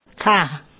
khâ